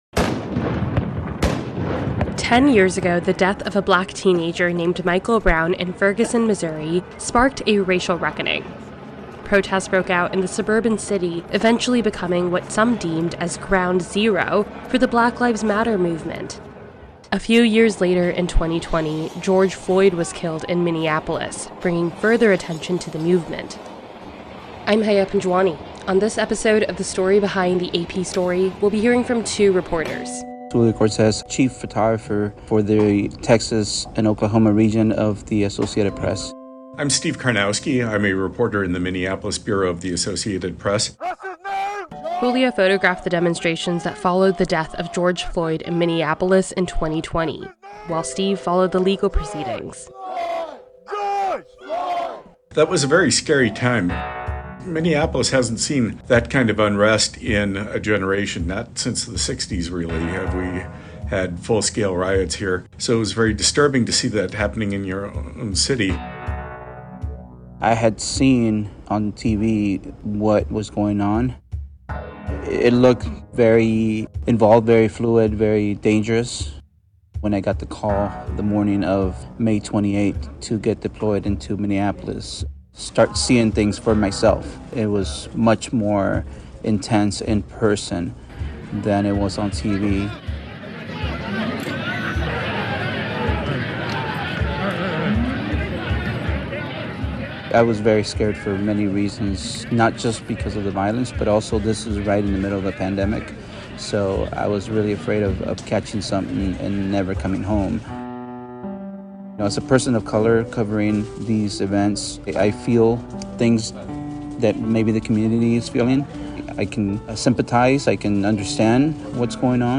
From Ferguson to Minneapolis, AP reporters recall flashpoints of the Black Lives Matter movement